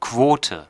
Ääntäminen
IPA: [ˈkʰʋ̥oː.tʰə]